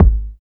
62 KICK 3.wav